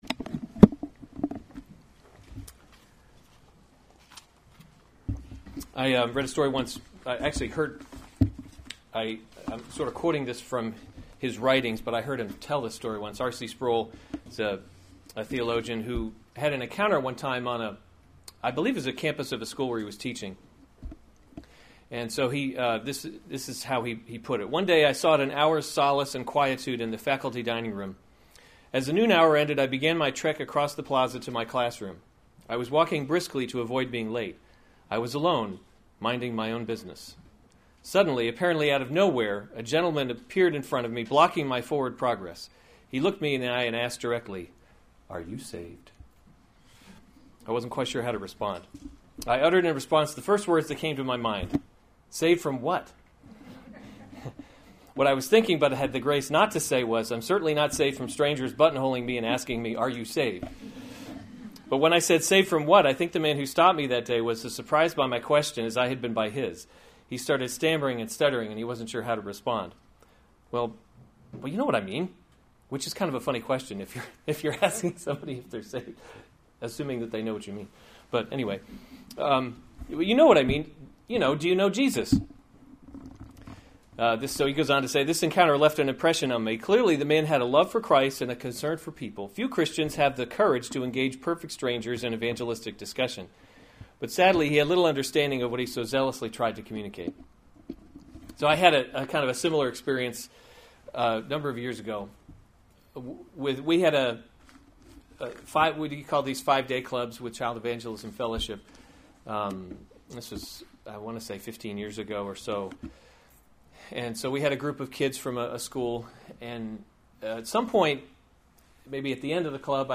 June 4, 2016 2 Thessalonians – The Christian Hope series Weekly Sunday Service Save/Download this sermon 2 Thessalonians 2:13-17 Other sermons from 2 Thessalonians Stand Firm 13 But we ought always […]